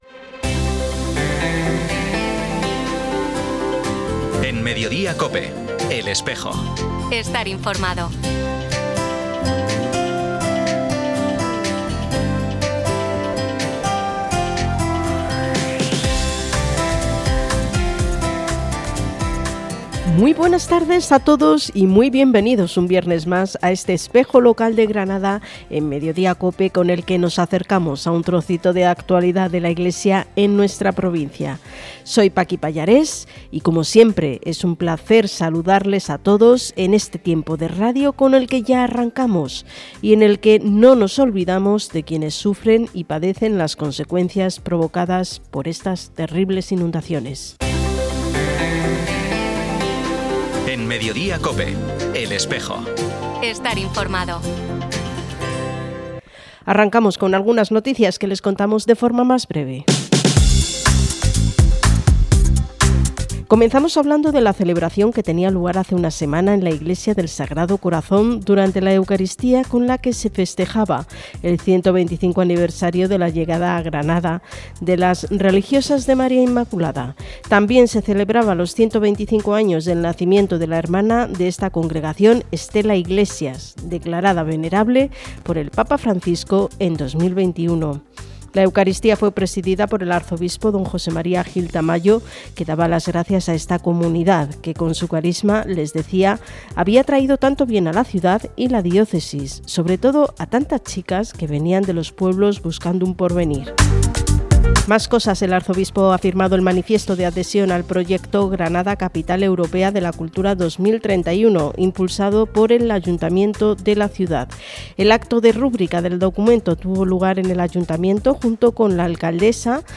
Programa emitido en COPE Granada y COPE Motril el 15 de noviembre de 2024.